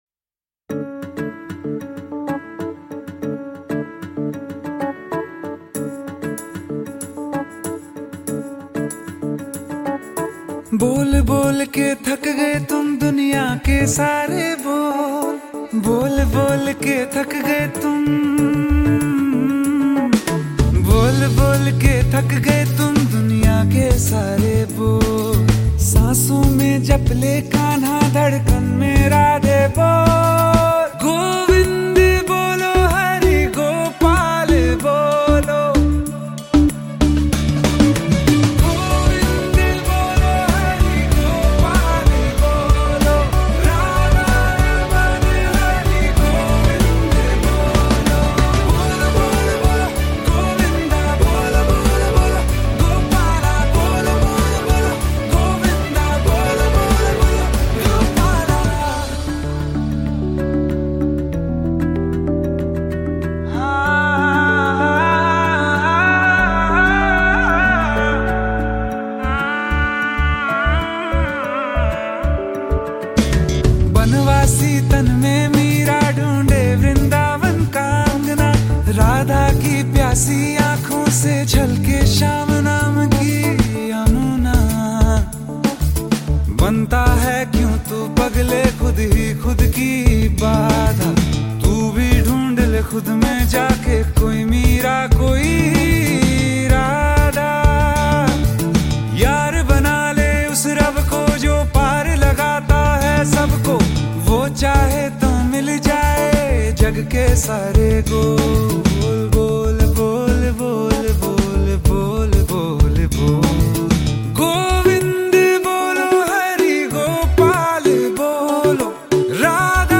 Categories Devotional Ringtones